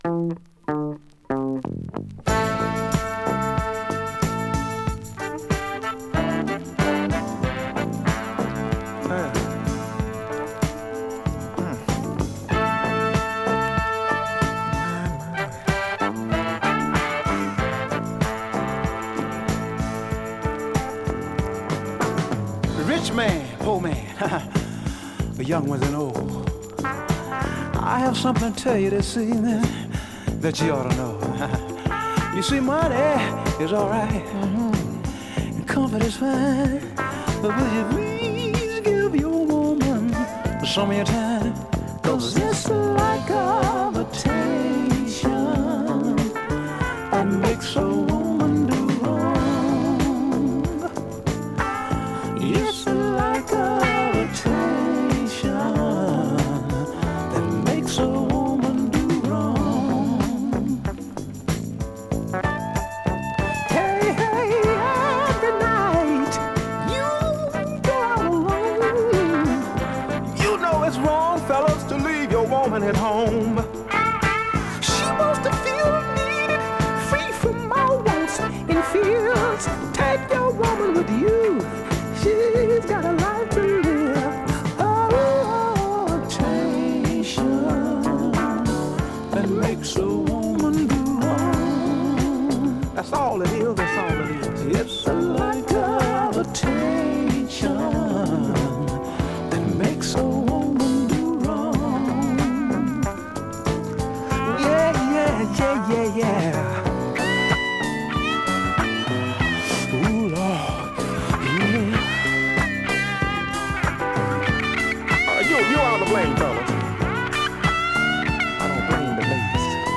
US盤 7"Single 45 RPM現物の試聴（両面すべて録音時間６分５３秒）できます。